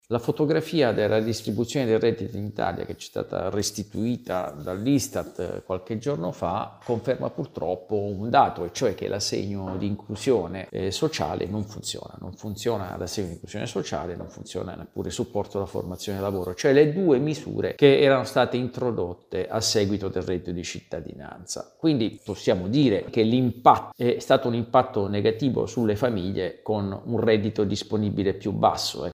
Non è tutto oro – A Milano-Cortina 2026 il peso delle medaglie non è uguale per tutti gli atleti. Il servizio